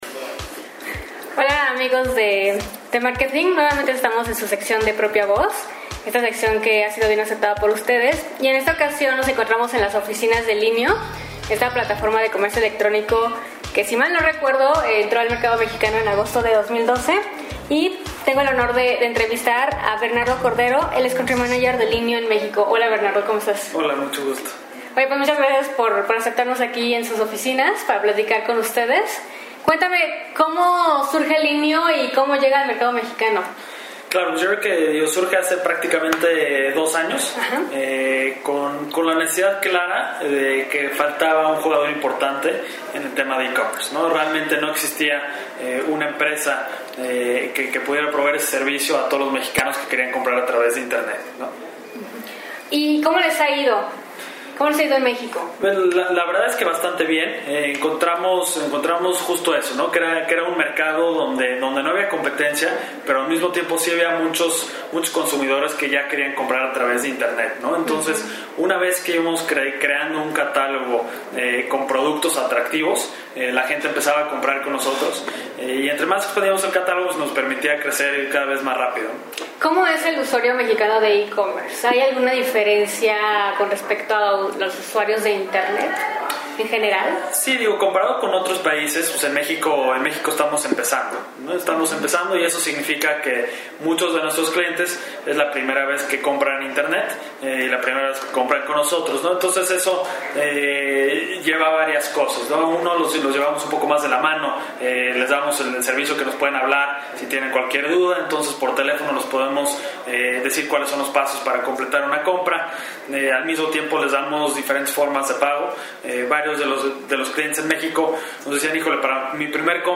México, D.F.- Tuvimos la oportunidad de visitar las oficinas de Linio, que a dos años de operaciones ha logrado posicionarse como la tienda en línea más grande del país, con más de 4.5 millones de visitas mensuales y 90 mil productos disponibles en diferentes categorías como electrónicos, ropa, hogar, niños y bebés, libros, entre otras.